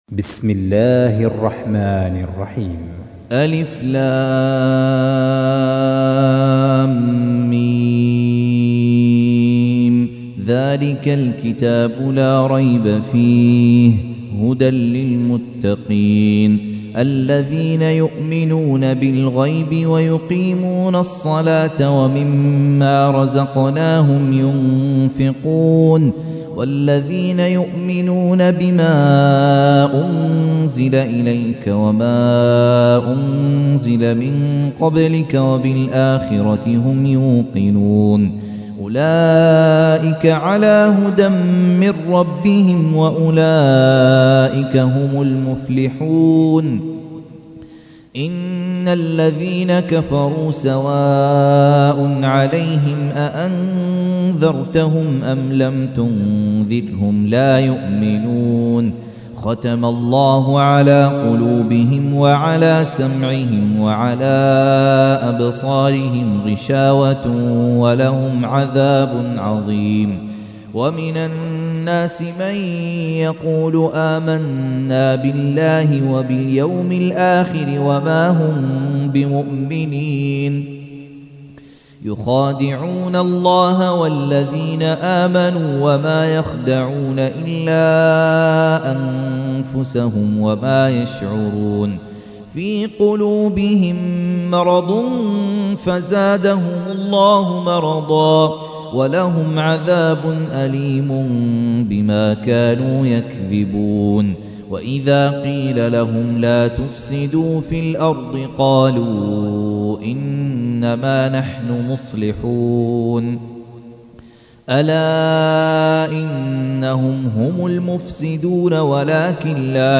تحميل : 2. سورة البقرة / القارئ خالد عبد الكافي / القرآن الكريم / موقع يا حسين